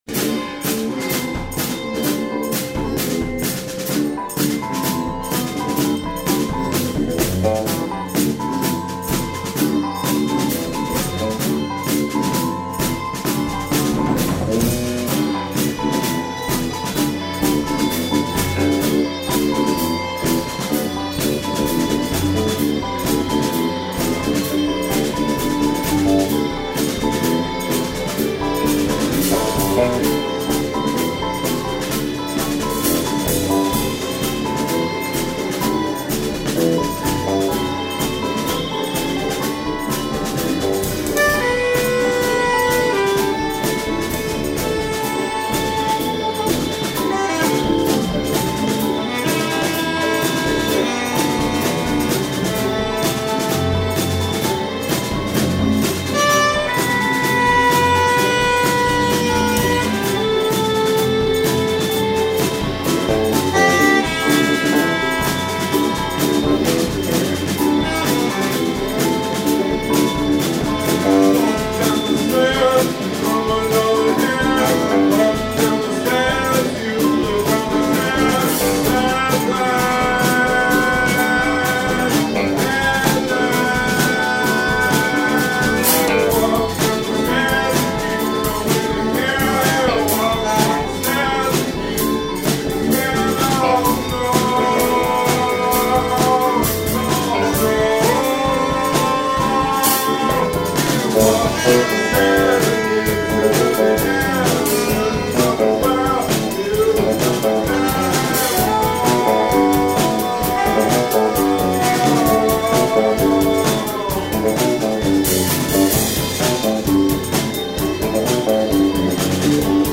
sax
bass/synth/leadguitar
sitar
all music improvised on site with minimal editing